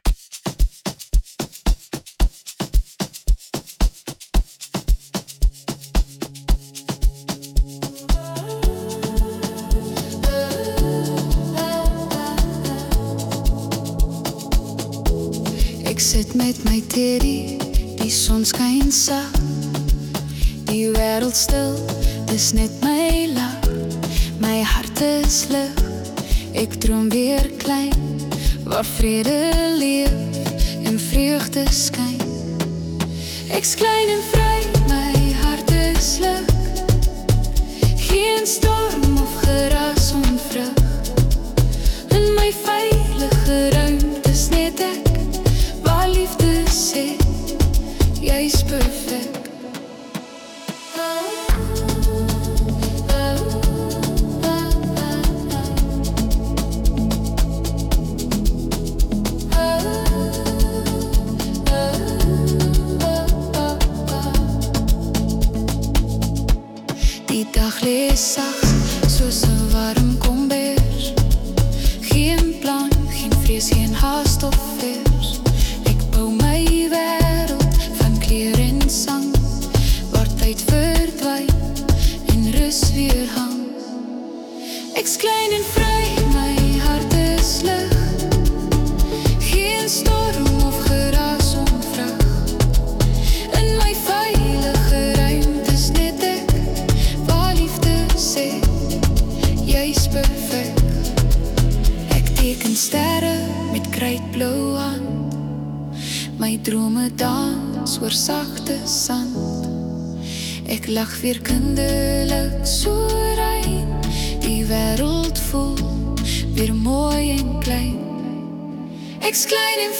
Genre: Amapiano
Vocal: KI